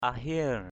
/a-hie̞r/ (d.)